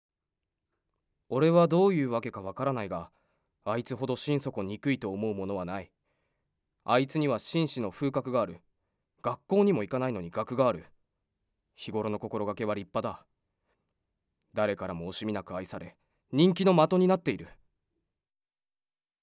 ボイスサンプル
セリフ@